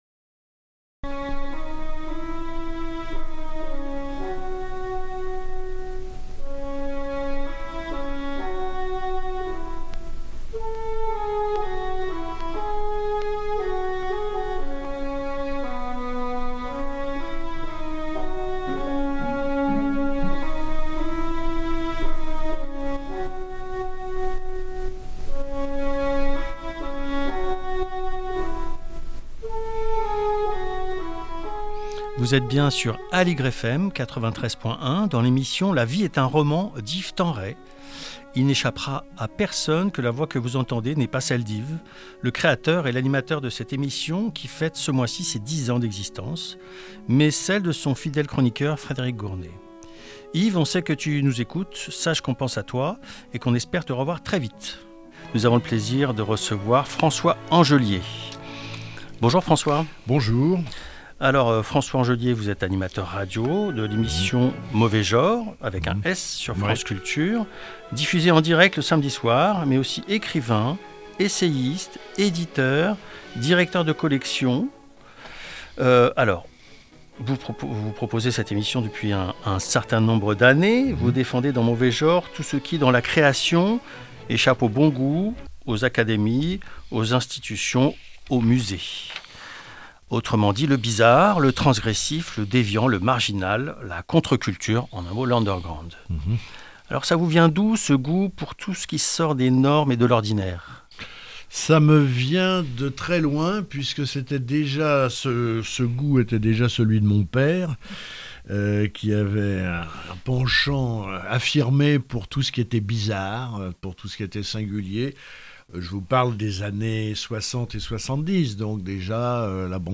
Auteur, éditorialiste, responsable de collection, François Angelier est interviewé